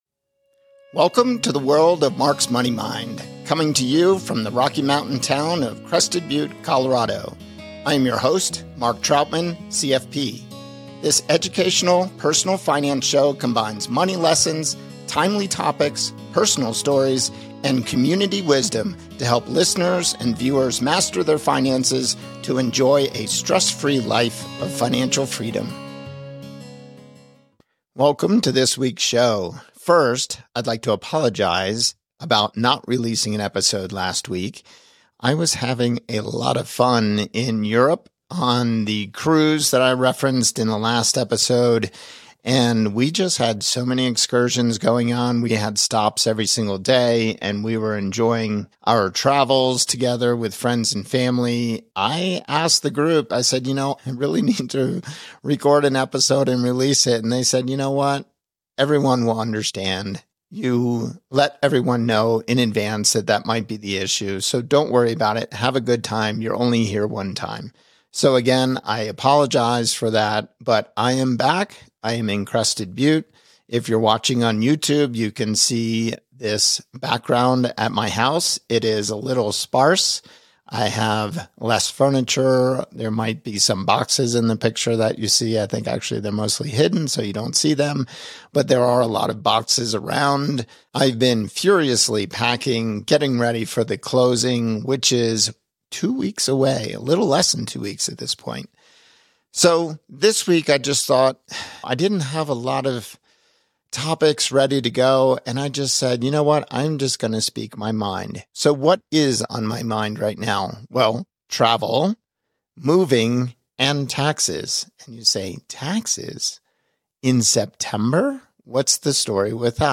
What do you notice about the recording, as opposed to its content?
from Crested Butte, Colorado